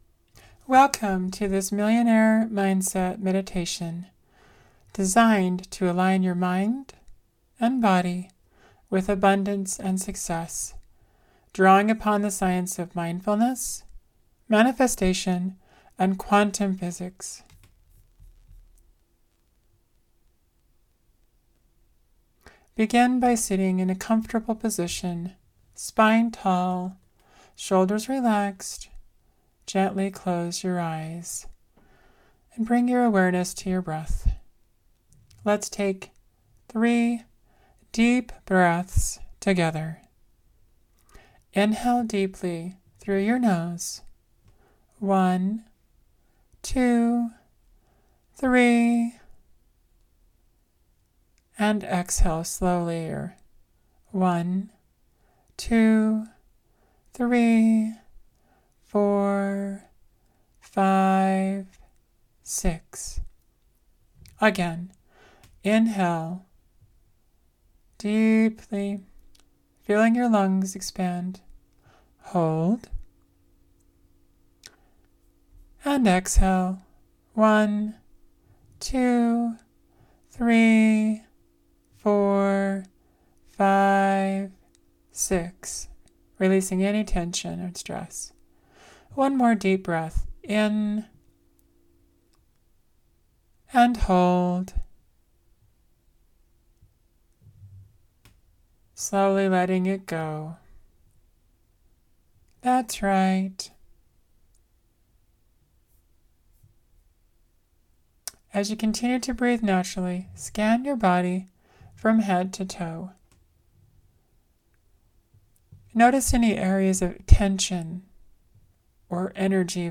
millionaire-mindset-meditation.mp3